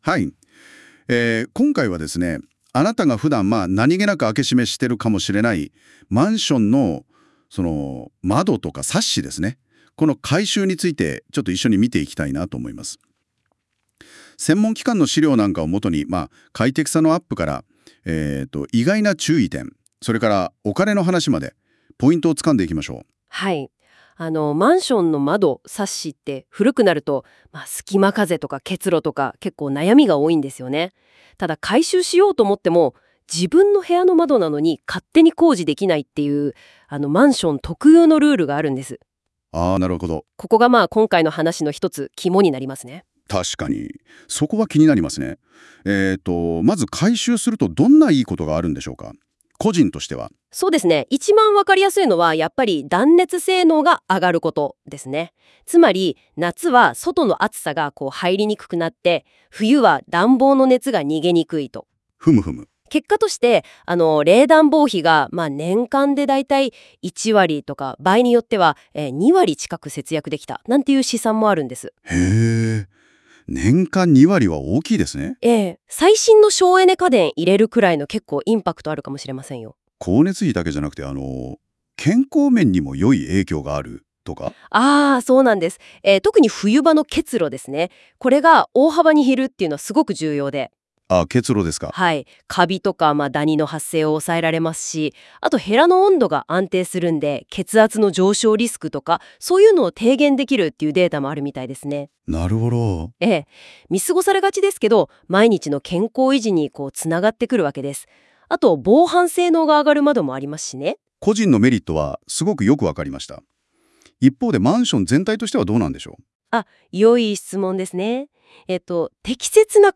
窓ガラス、窓サッシ交換のポイントを音声解説（2025年6月1日追加）
窓ガラスや窓サッシを交換するメリットや、注意点、費用について分かりやすくナレーターが解説していますので、コラムの参考に聴いていただければと思います。